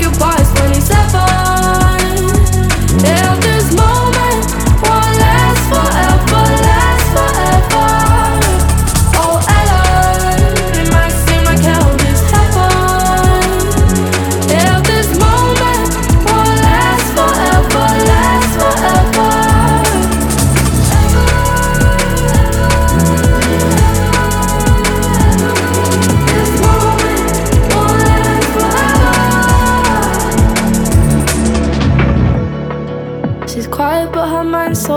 2024-10-25 Жанр: Танцевальные Длительность